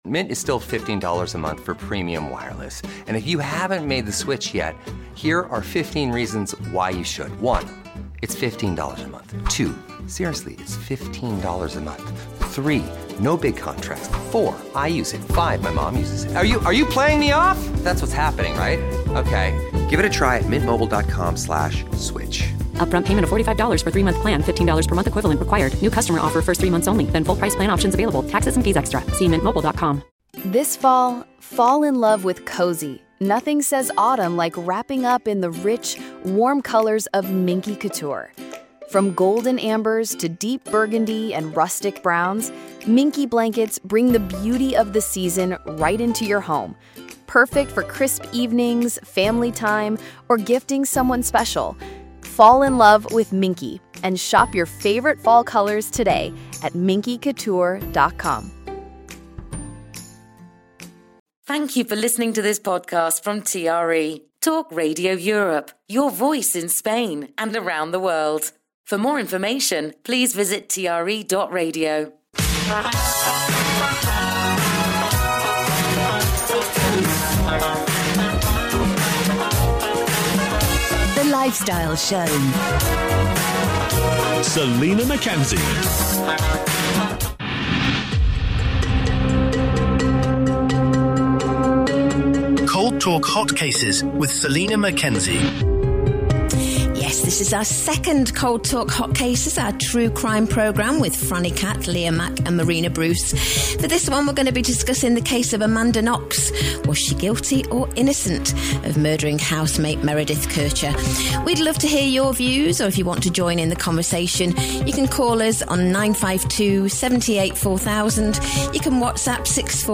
panel of guests